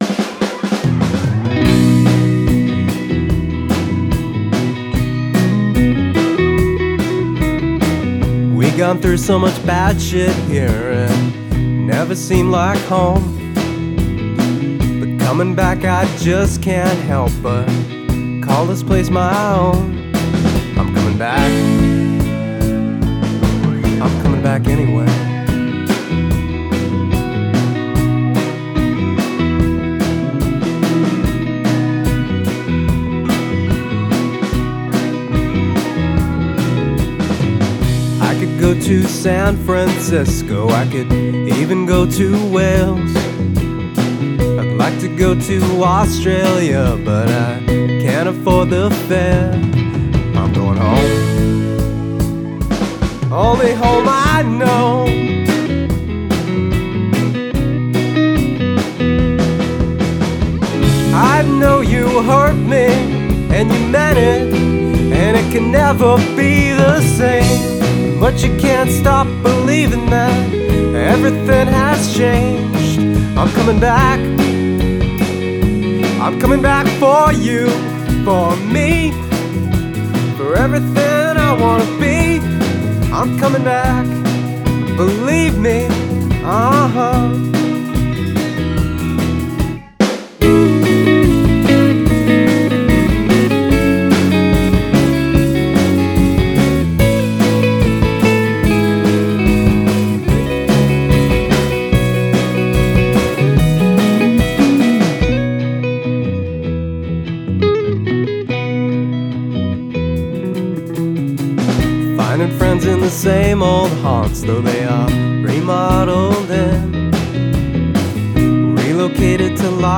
Chapel Hill NC Rock Band